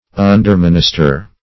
Search Result for " underminister" : The Collaborative International Dictionary of English v.0.48: Underminister \Un`der*min"is*ter\, v. t. To serve, or minister to, in a subordinate relation.